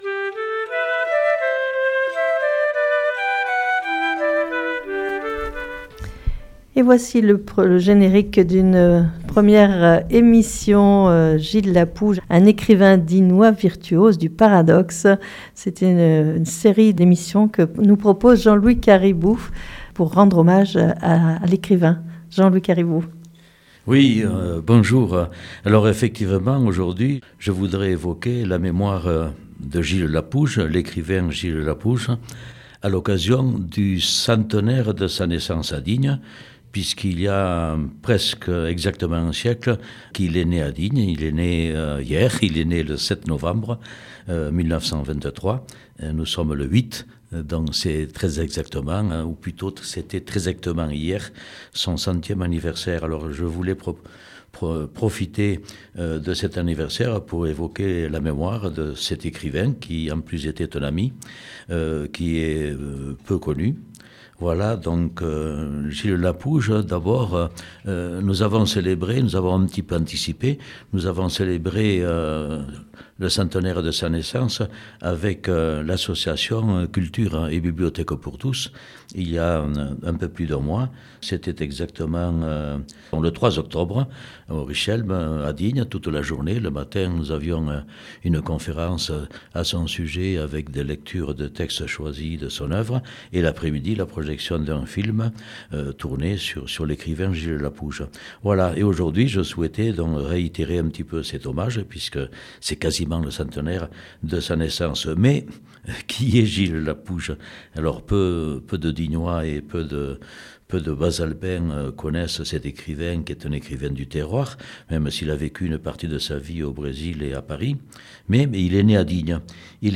pour Fréquence Mistral Digne 99.3